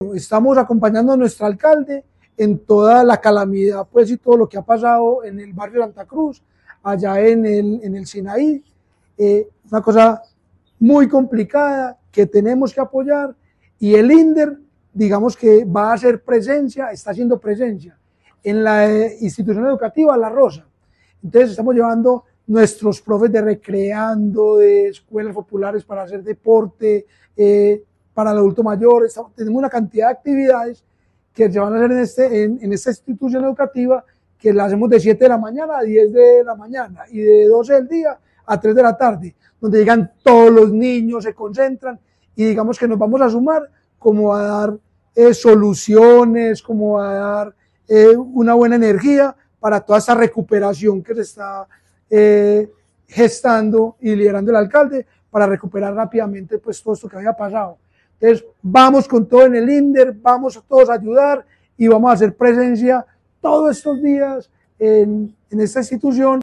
Palabras de Eduardo Silva Meluk, director del Inder